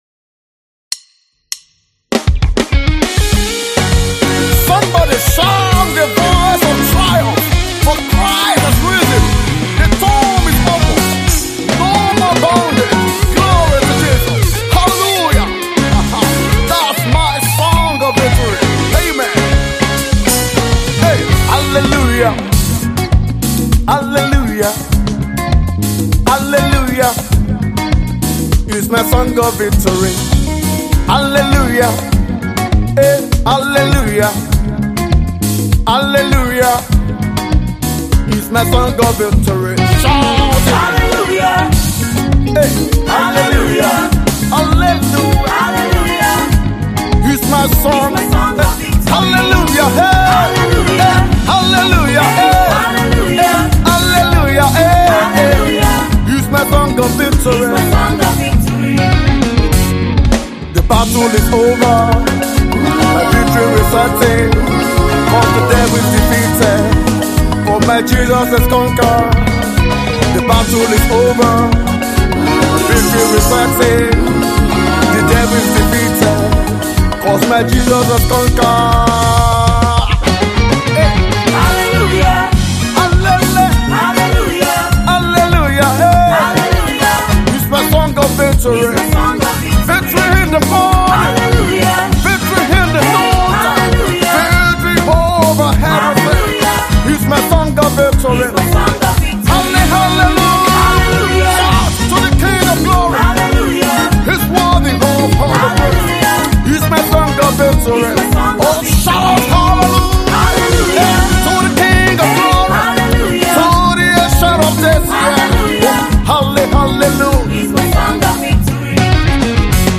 cool lyric-orchestrated praise song